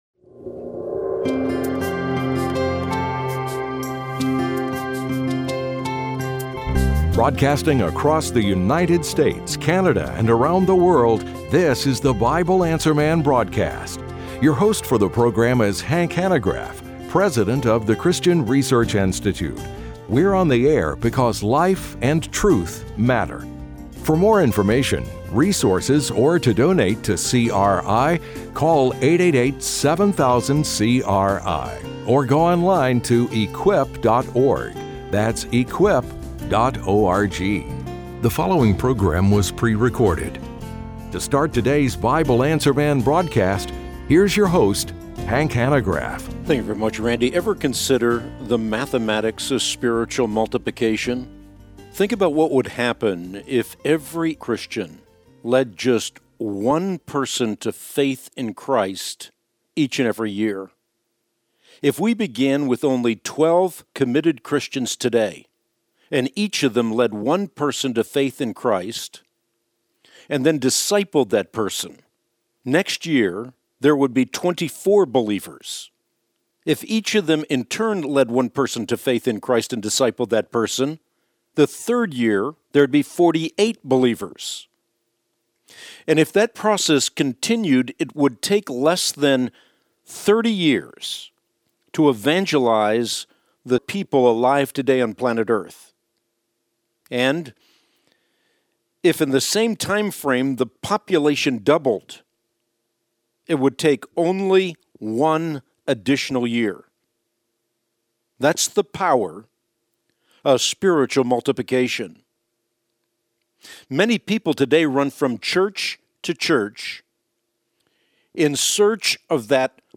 Hank also answers the following questions: